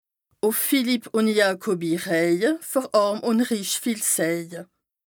Bas Rhin
Ville Prononciation 67
Strasbourg